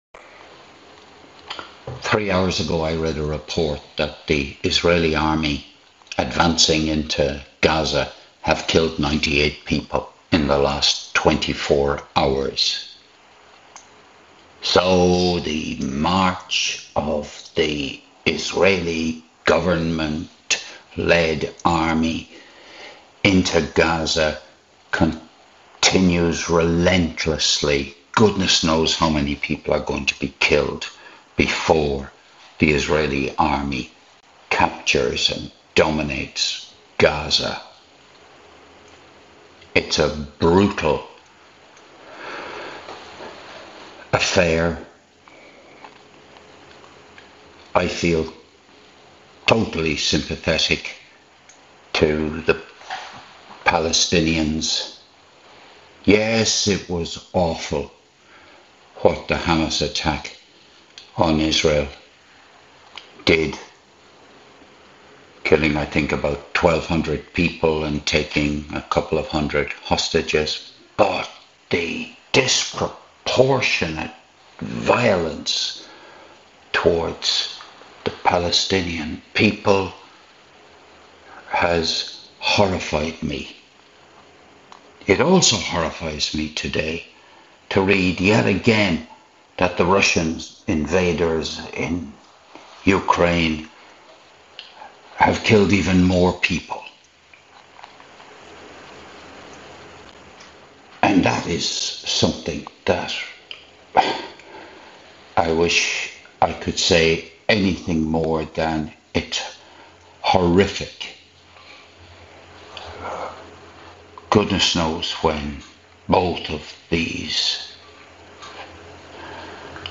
This was recorded while showering on the morning of Saturday 22nd of February 2025